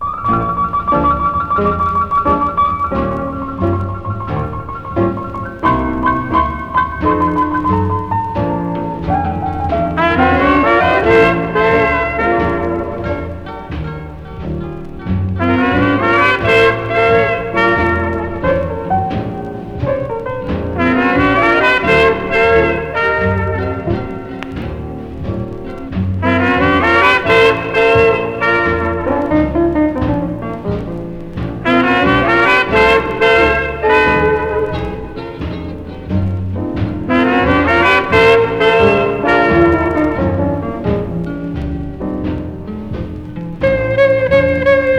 そんな1937年から1939年のニューヨークで夜な夜な炸裂したジャズの旨味がジューワーっと溢れ出してます。
Jazz　USA　12inchレコード　33rpm　Mono